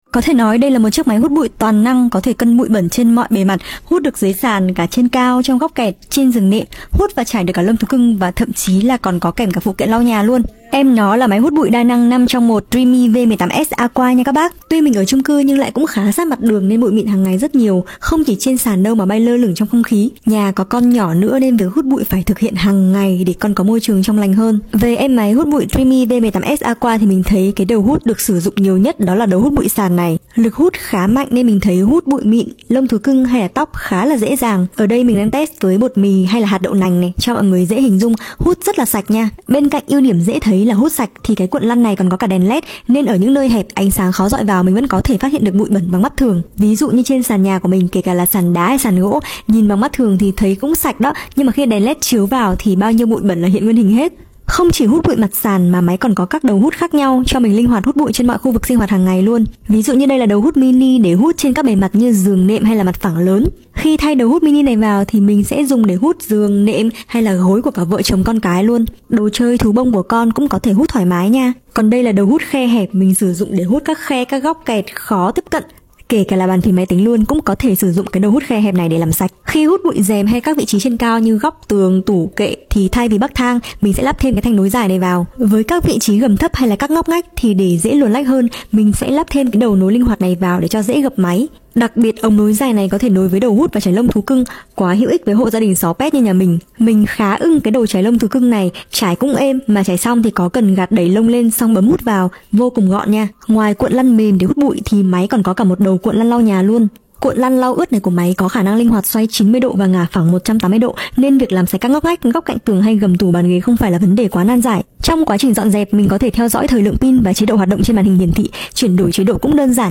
Hlasová část